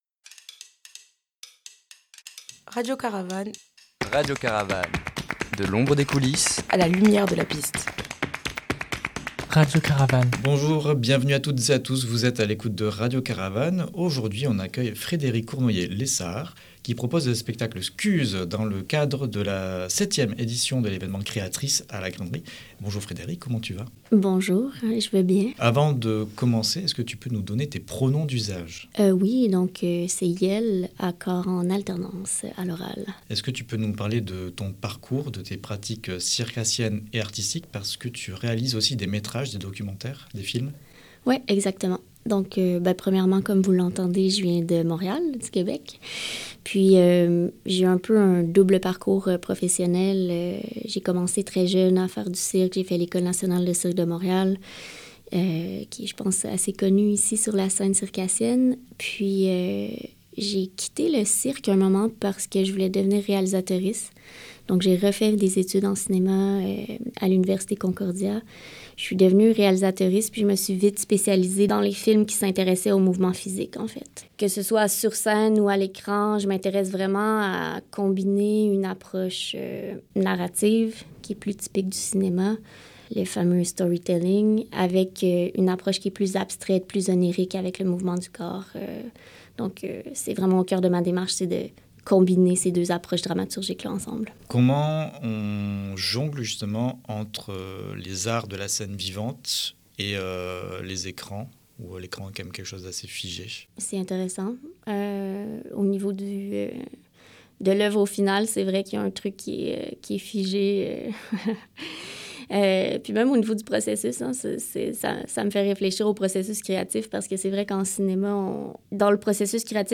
Un témoignage à écouter juste en-dessous.
en interiew au studio radio